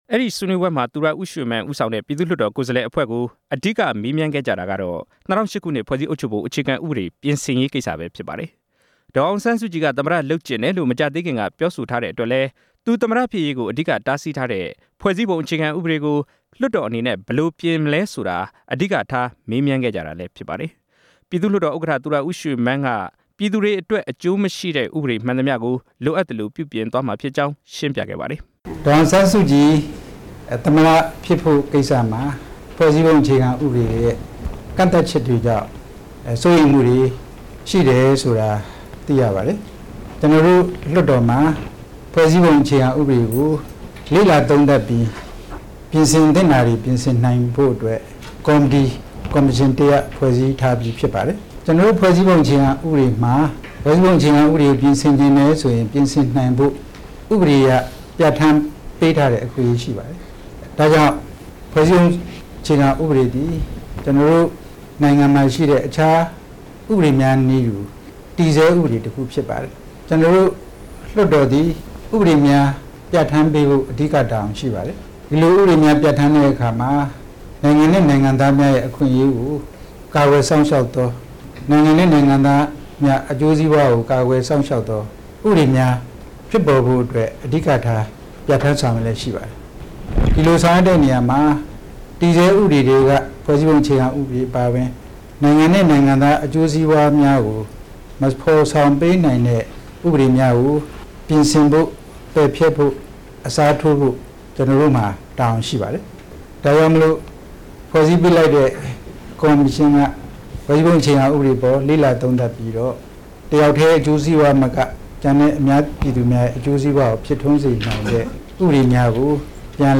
ပြည်သူ့လွှတ်တော် ဥက္ကဌ သူရဦးရွှေမန်းရဲ့ ပြောကြားချက်
အမေရိကန် ပြည်ထောင်စု ဝါရှင်တန်ဒီစီမြို့တော် ဝုဒရိုး ဝီလဆင် စင်တာ(Woodrow Wilson Center) မှာ မနေ့ညနေက ကျင်းပတဲ့ မြန်မာ့နိုင်ငံရေး လက်ရှိ အခြေအနေအပေါ် ပြည်ထောင်စုလွှတ်တော်ရဲ့ အမြင် ခေါင်းစဉ်နဲ့ ဆွေးနွေးပွဲမှာ ပြည်သူ့လွှတ်တော် ဥက္ကဌ သူရဦးရွှေမန်းက အခုလို ပြောကြားလိုက် တာ ဖြစ်ပါတယ်။